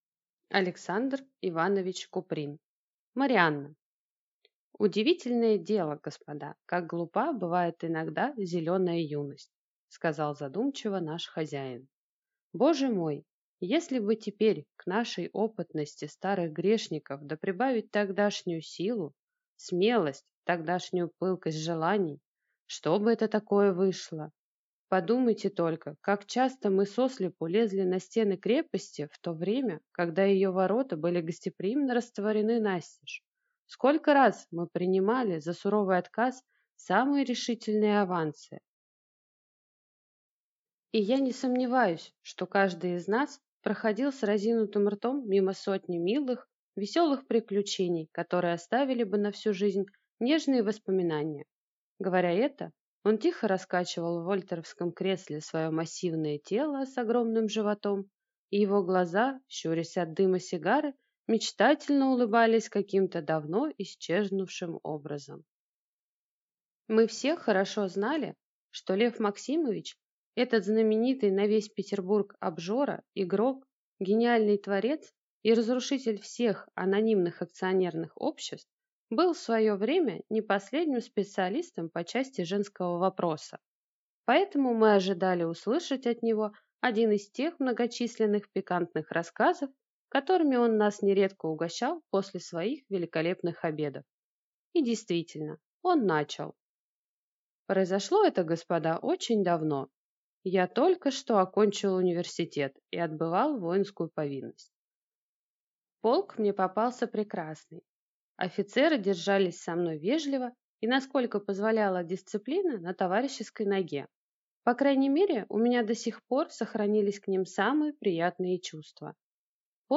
Аудиокнига Марианна | Библиотека аудиокниг